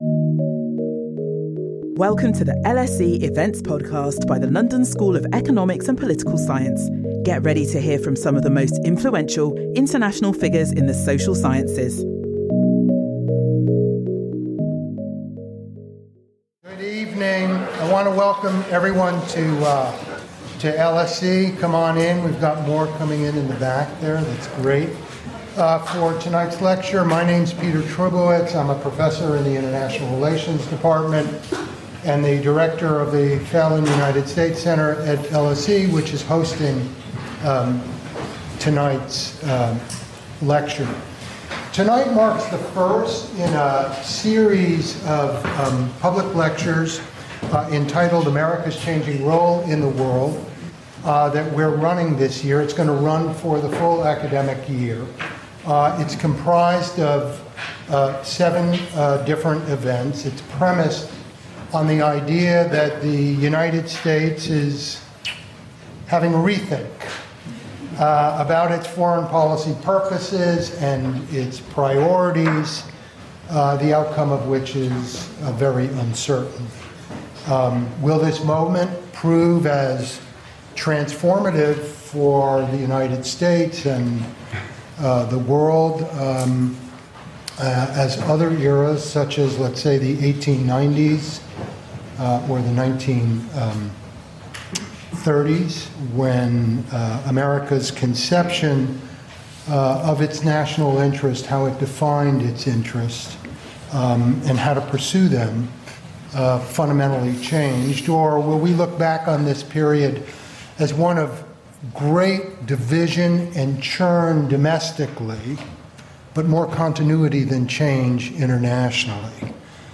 In this lecture, Charles Kupchan considers whether a divided America can find the middle ground over foreign policy.